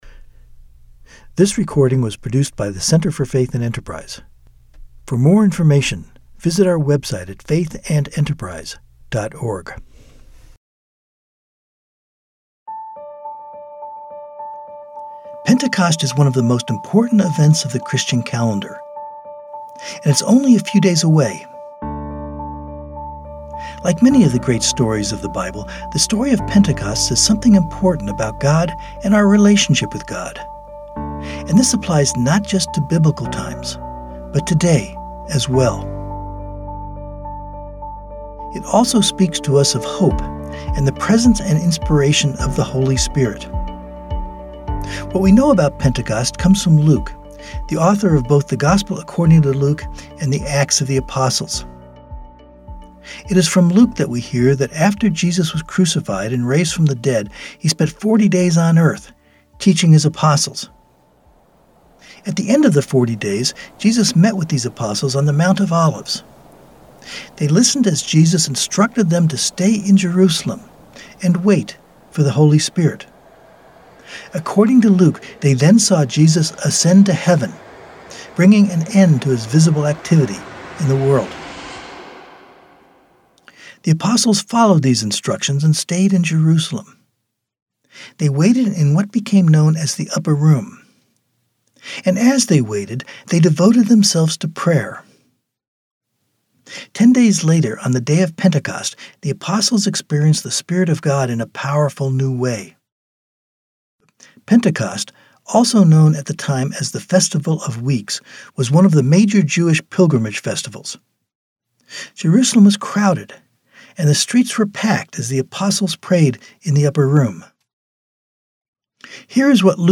This year, we are offering a recorded reflection as a way to help you and your church prepare for Pentecost.